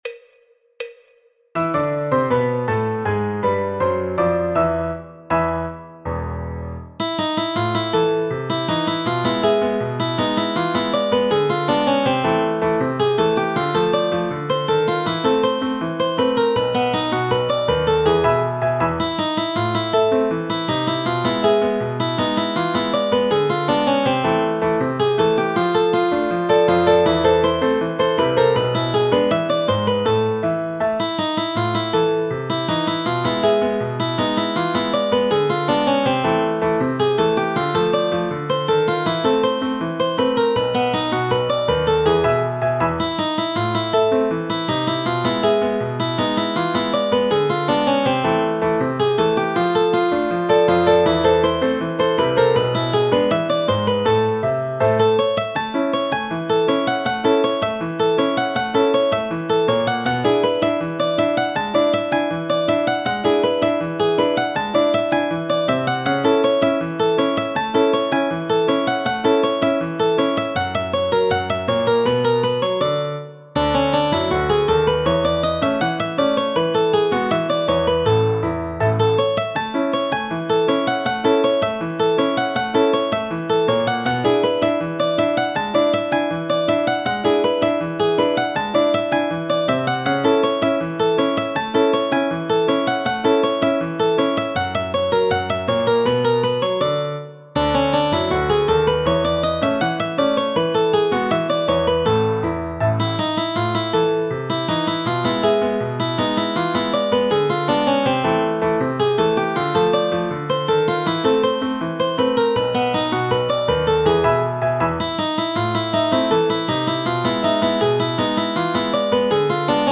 Punteo en La menor y La Mayor
Choro, Jazz, Popular/Tradicional